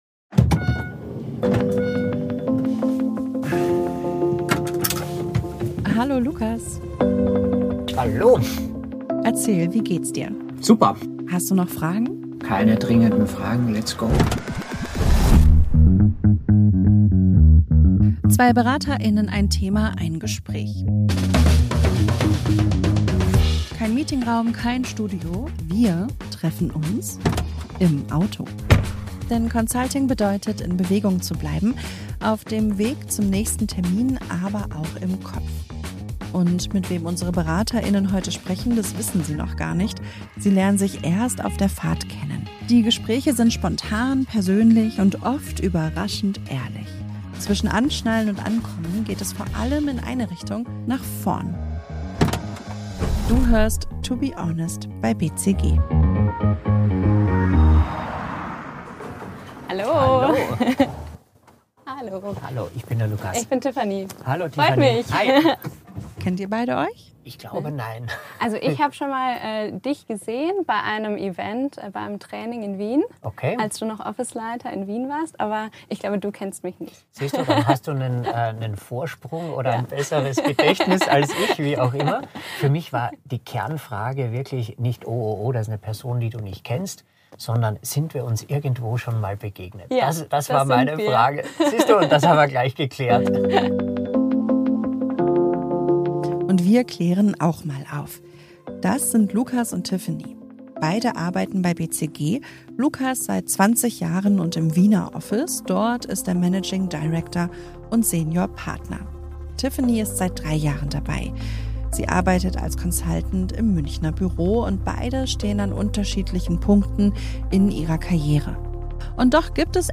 Beschreibung vor 3 Wochen Ein Gefühl, das viele kennen, aber kaum jemand anspricht: Selbstzweifel trotz sichtbarem Erfolg. In der ersten Folge von „TBH by BCG“ sprechen zwei Berater:innen über die Angst, nicht gut genug zu sein, auch Imposter-Syndrom genannt.